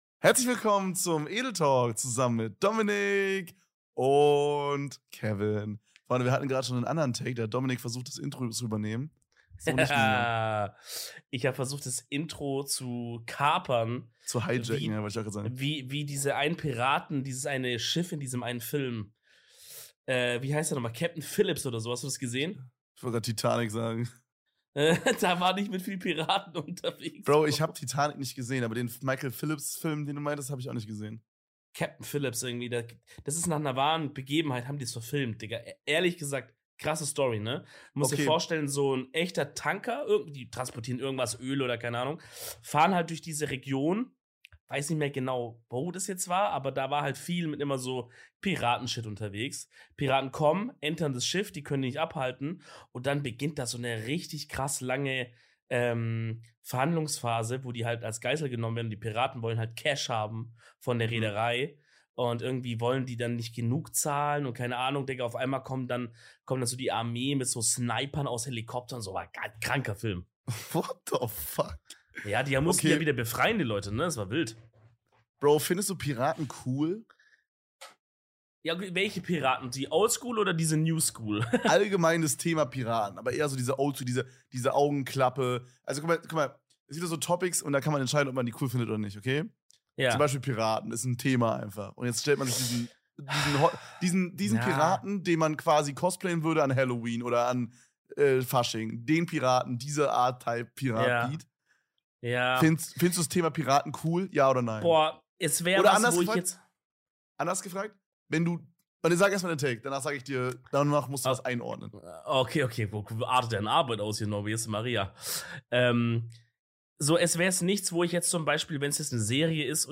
Wieder mal Nachts aufgenommen, wieder mal mehr Traum als Realität, wieder mal sexy Content für eure Weihnachts-Ohren!